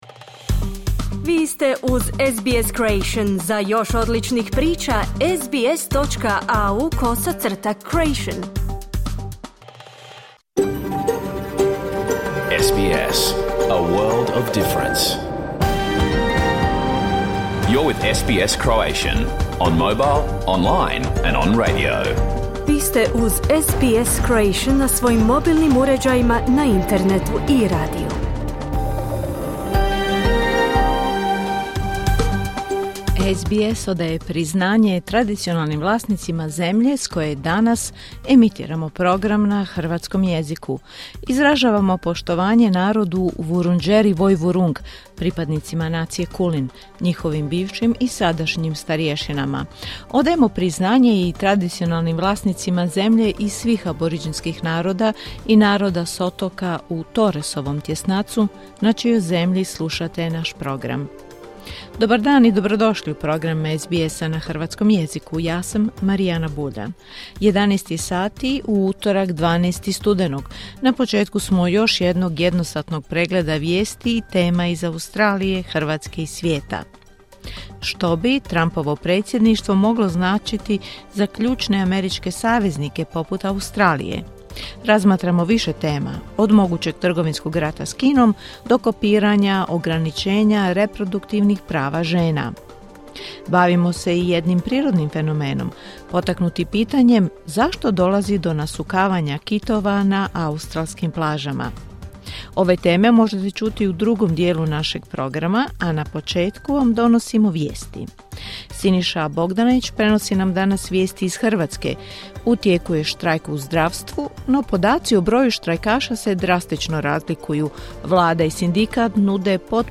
Pregled vijesti i aktualnih tema iz Australije, Hrvatske i ostatka svijeta. Emitirano uživo na radiju SBS1 u utorak, 12. studenog u 11 sati, po istočnoaustralskom vremenu.